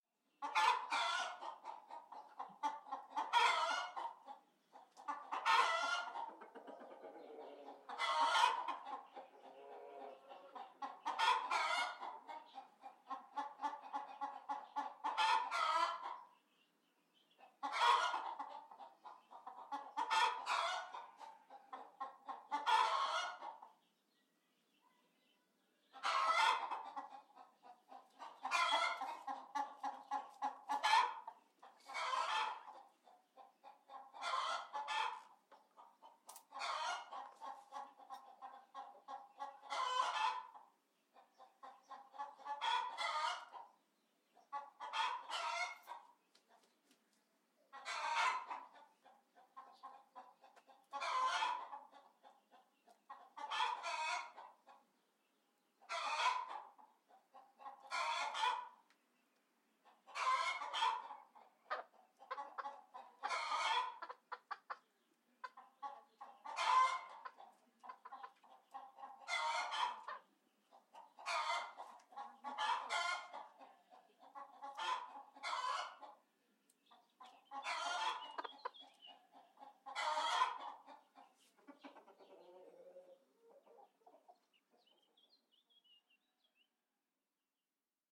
Chicken 56092 (audio/mpeg)